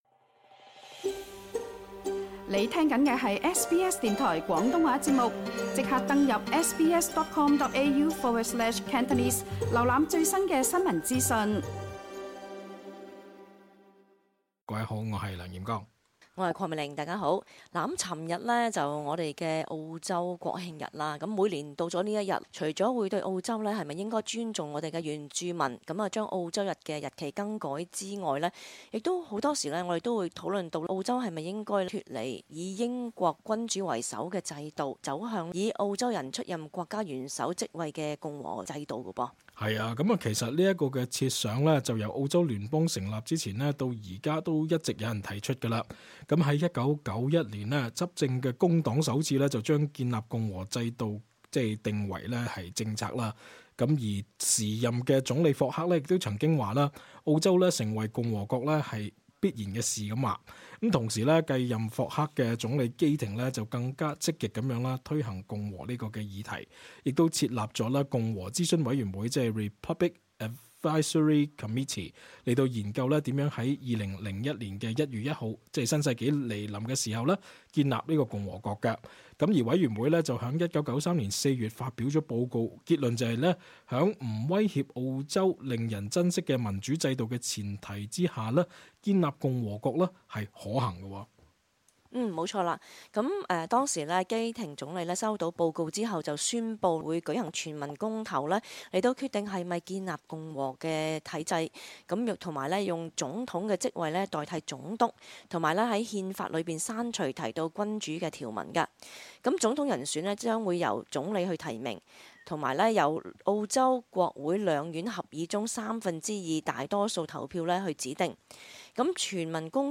cantonese_talkback_jan_27_final_upload_0.mp3